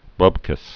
(bŭbkəs)